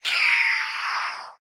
Minecraft Version Minecraft Version snapshot Latest Release | Latest Snapshot snapshot / assets / minecraft / sounds / mob / vex / death1.ogg Compare With Compare With Latest Release | Latest Snapshot
death1.ogg